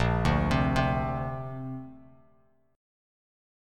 A#add9 chord